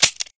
sgg_dryfire_01.ogg